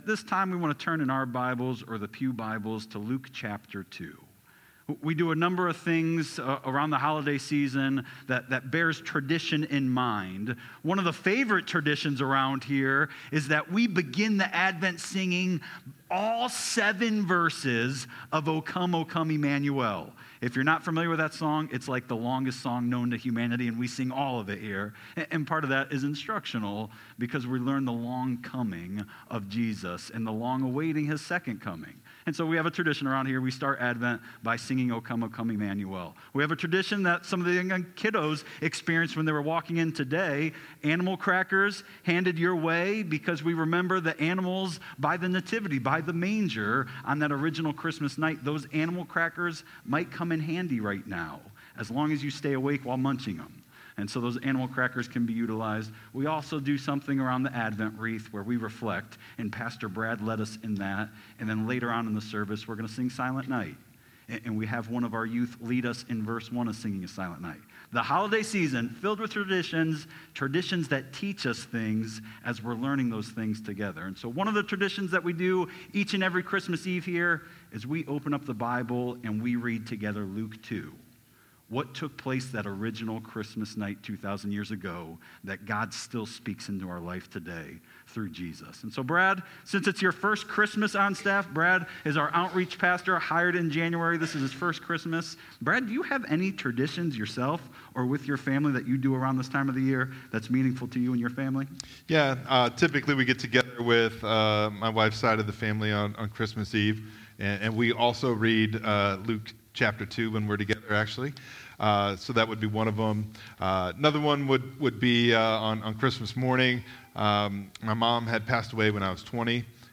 Sermons | First Church Bellevue
Question & Answer